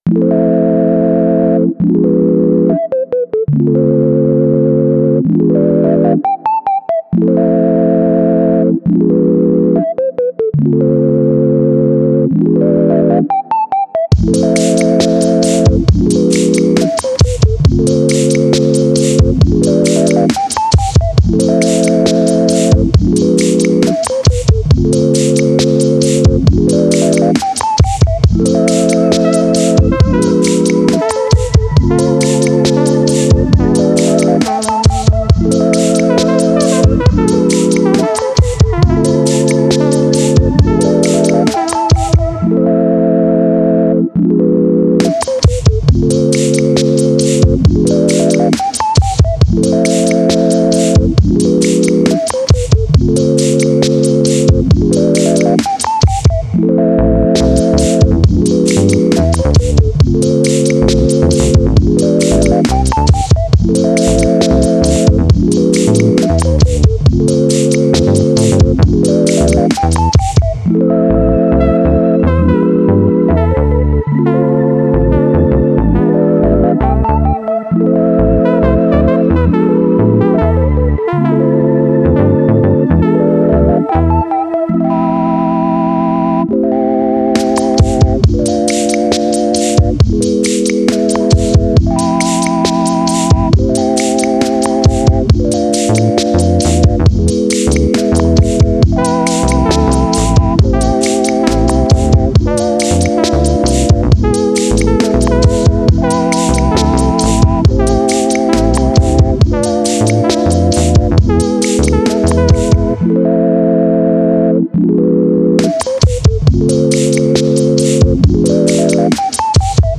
Laidback beats carve out a quirky groove.